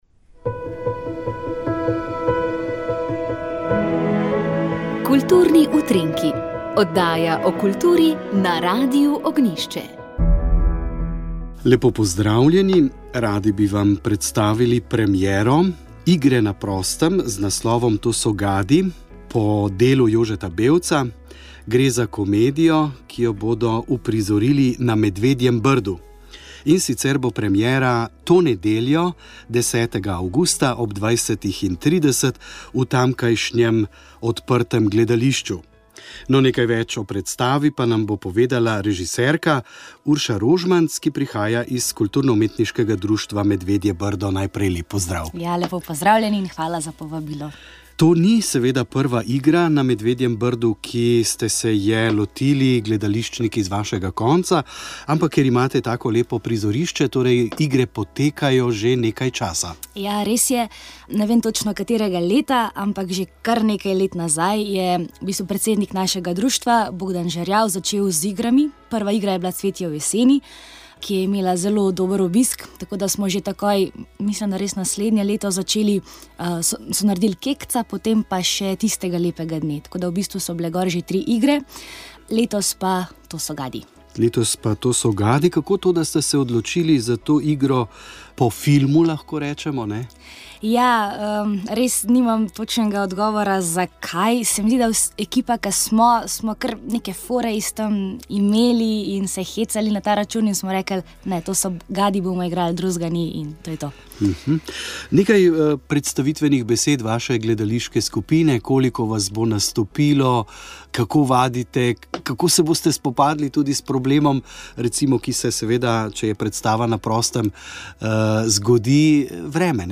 Rožni venec
Molili so radijski sodelavci.